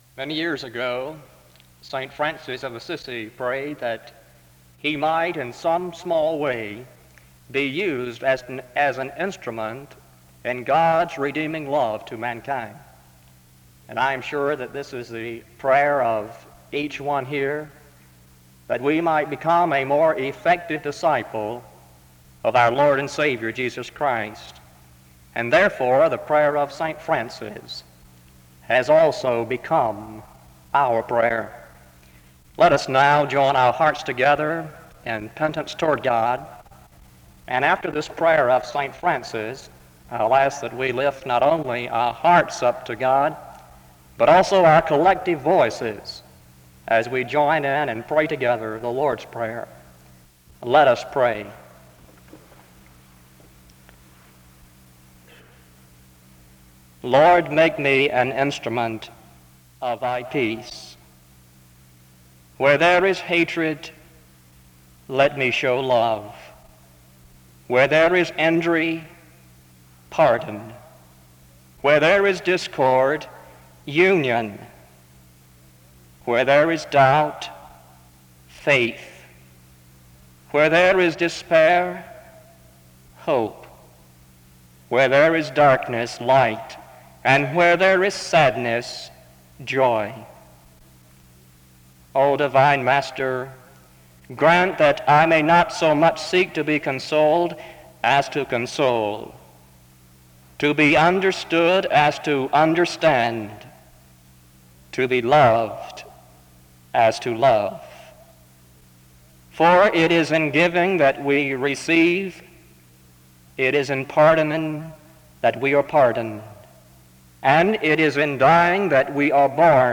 SEBTS Chapel - Student Service February 11, 1969